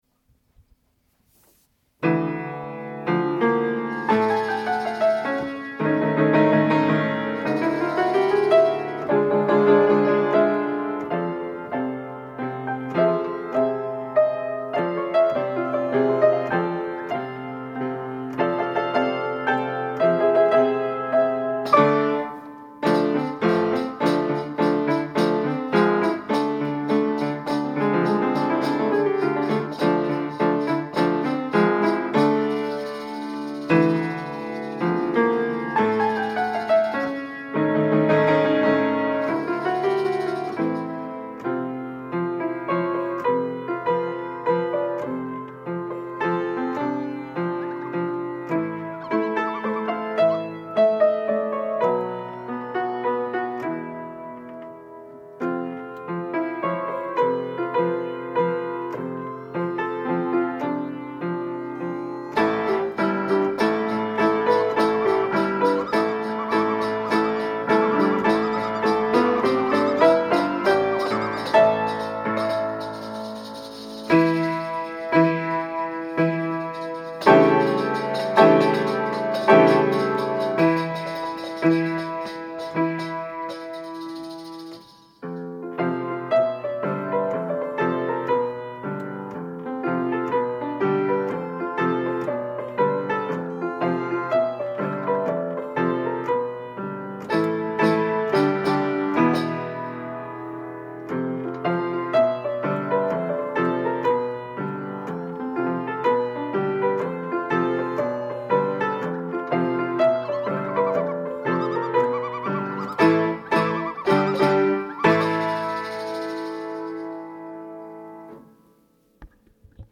MP3 of the only recorded performance, Steiner Symphony 2012.